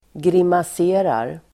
Ladda ner uttalet
grimasera verb, make faces, grimaceGrammatikkommentar: A &Uttal: [grimas'e:rar] Böjningar: grimaserade, grimaserat, grimasera, grimaserarDefinition: göra grimaser